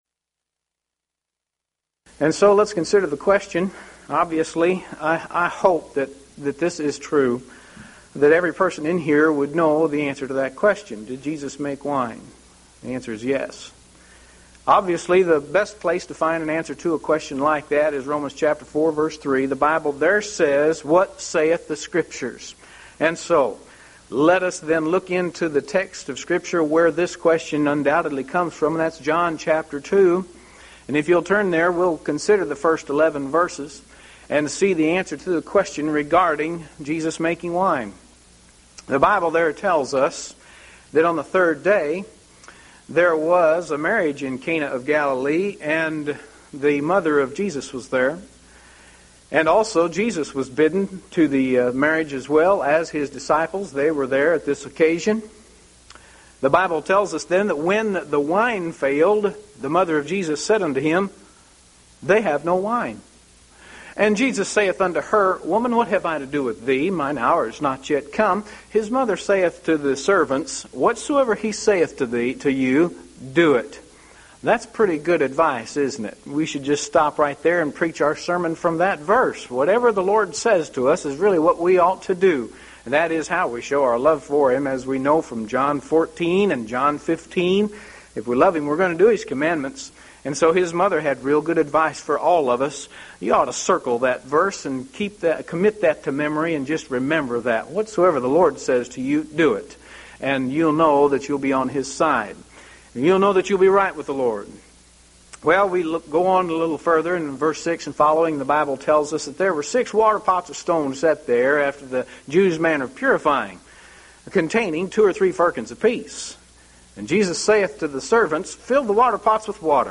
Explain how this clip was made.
Event: 1998 Mid-West Lectures